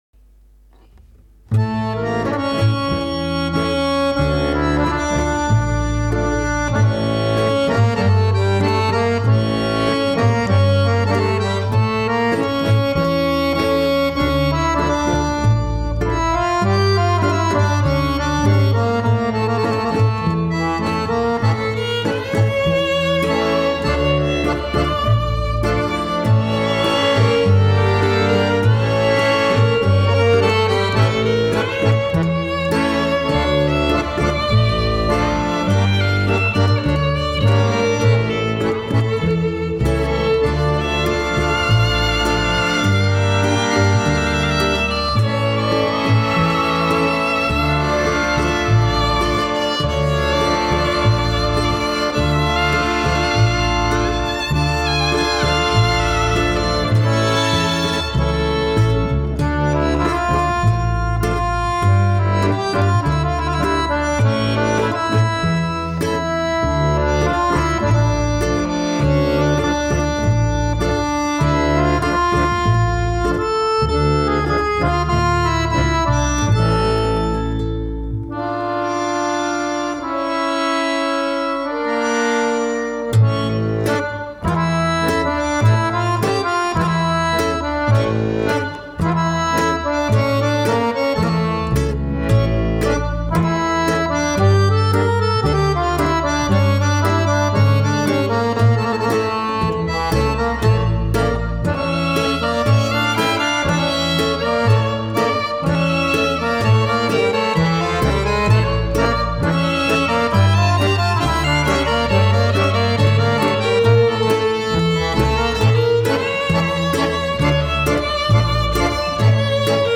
Akustična etno skupina